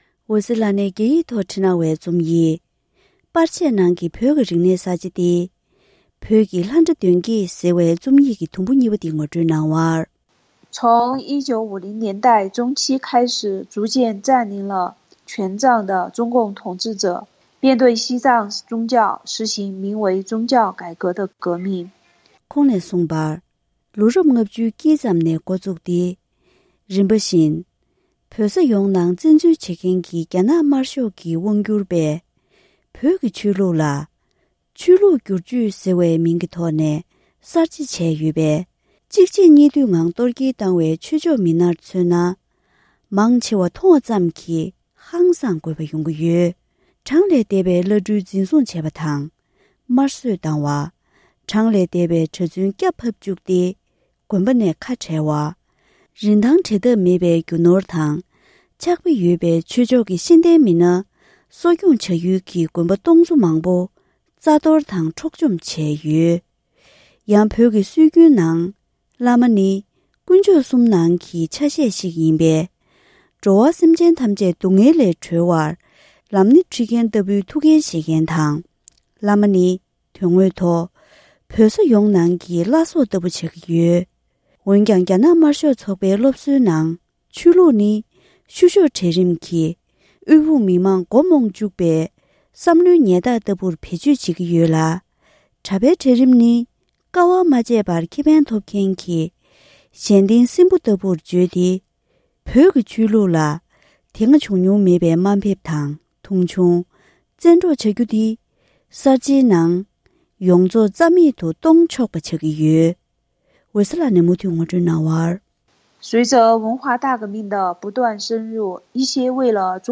བོད་ཀྱི་ལྷ་འདྲེ་གདོན་བགེགས་༢། སྒྲ་ལྡན་གསར་འགྱུར།